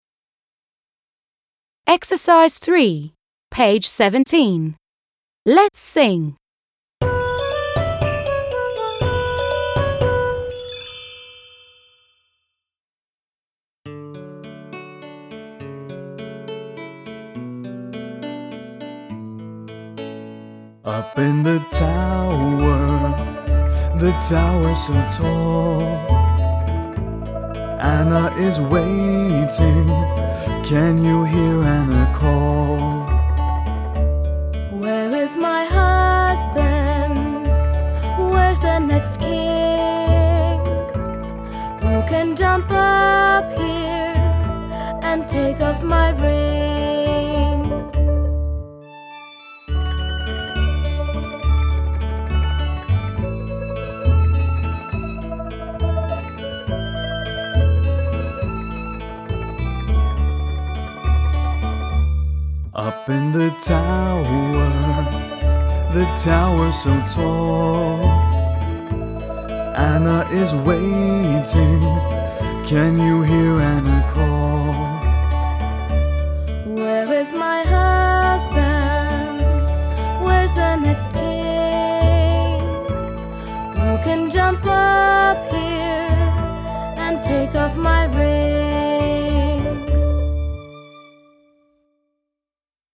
3. Let’s sing! − Давай споем!